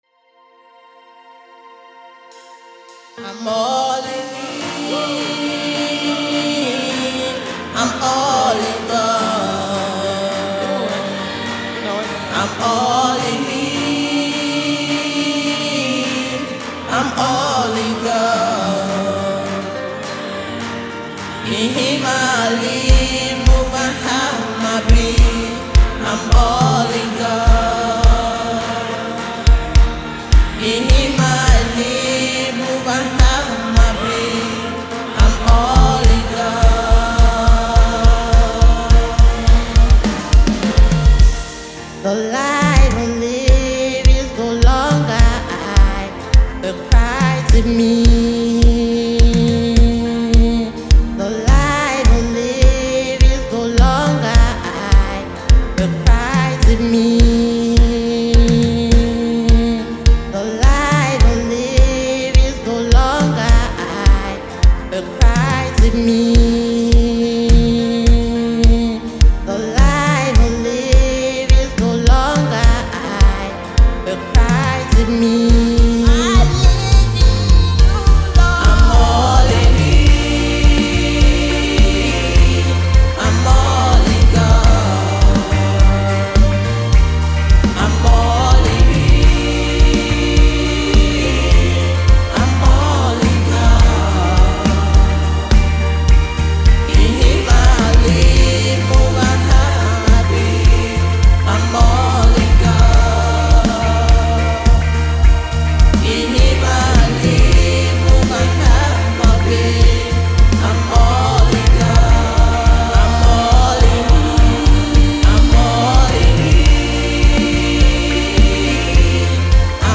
soulful voice
uplifting melodies and passionate delivery